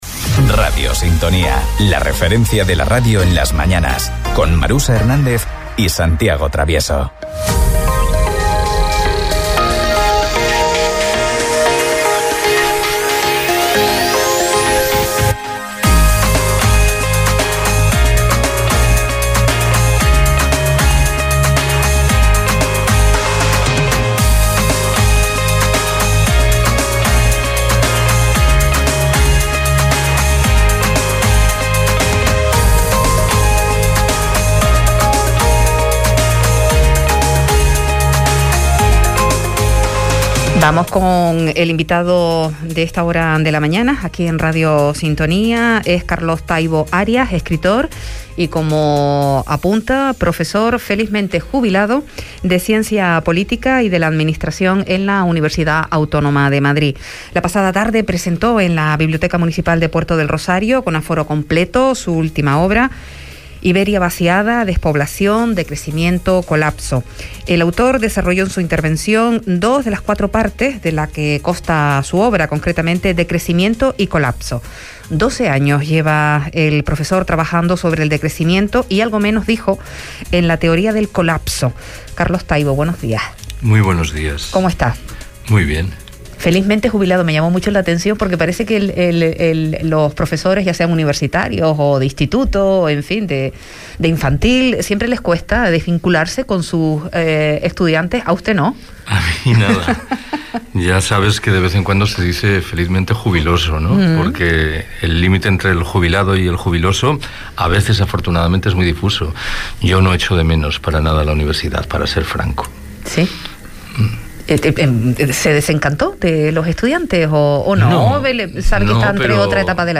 Recibimos en la radio a Carlos Taibo Arias, escritor, profesor, de Ciencia Política y de la Administración
Entrevistas